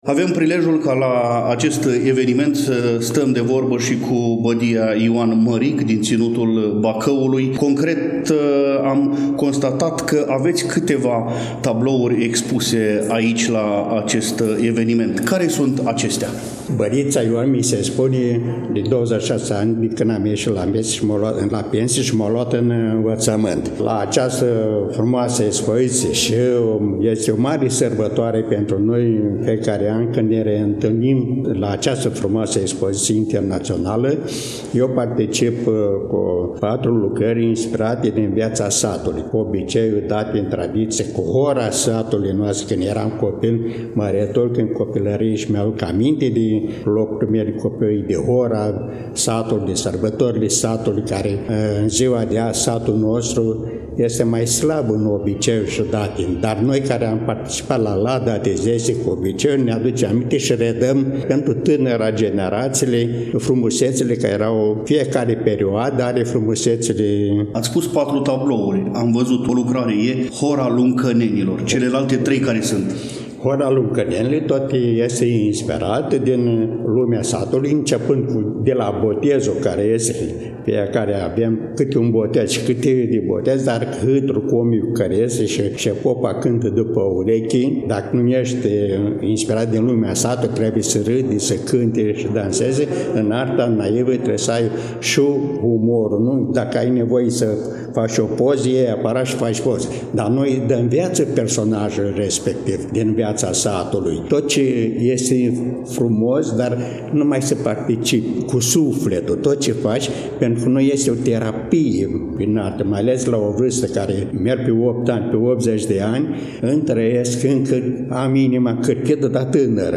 Dragi prieteni, vă reamintim că relatăm de la vernisajul Expoziției Naționale de Artă Naivă „Saloanele Moldovei”.